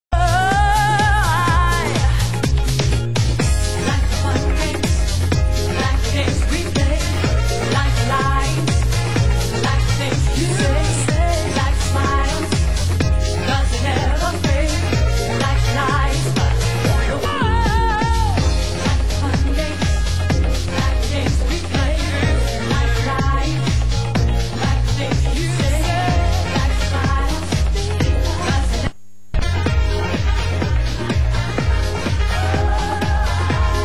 Genre: House
Genre: UK House
vocal club mix classic mix vox dub